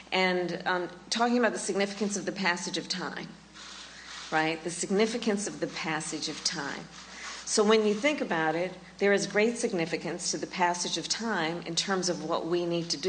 Kamala Harris Speech